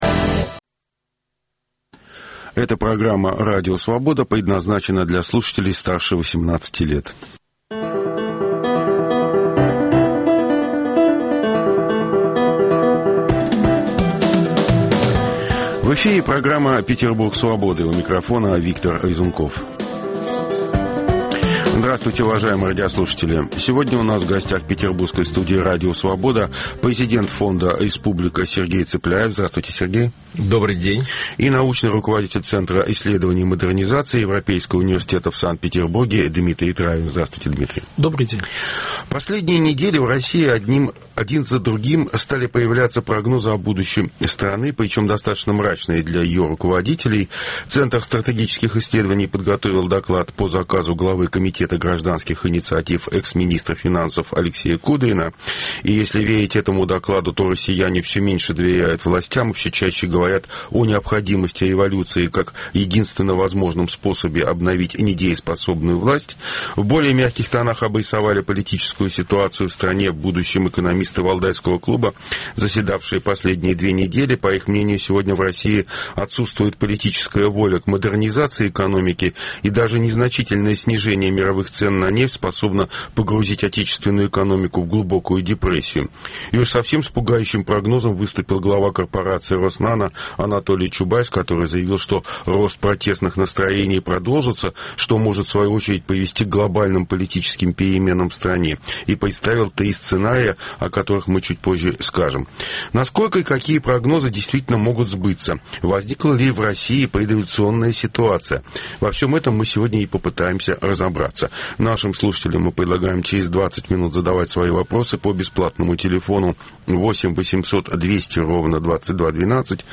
Круглый стол: Петербург Свободы